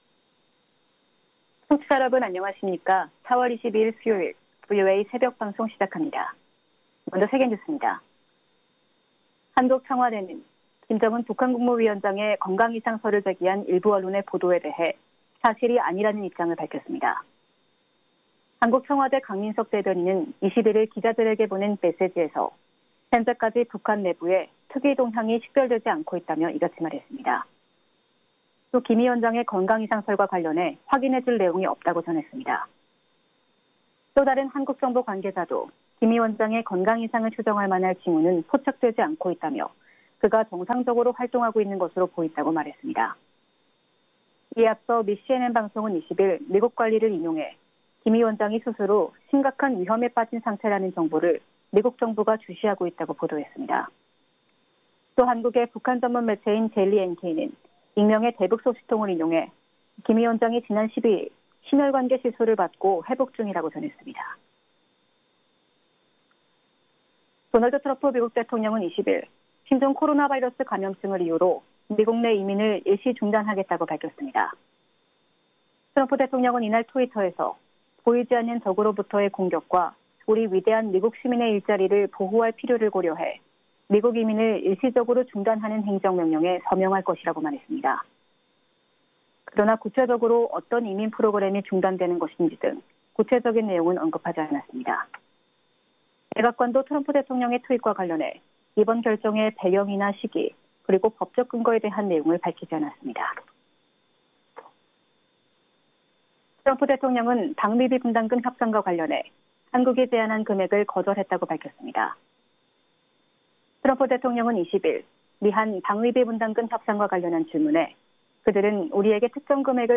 VOA 한국어 '출발 뉴스 쇼', 2020년 4월 22일 방송입니다. 김정은 북한 국무위원장이 최근 심혈관 시술을 받고 위중한 상태라는 일부 언론 보도가 나왔으나 한국 정부는 김 위원장의 건강 이상징후는 포착된 게 없다고 밝혔습니다. 도널드 트럼프 미국 대통령은 방위비 분담금 협상과 관련해 한국이 제안한 금액을 거절했다고 밝혔습니다.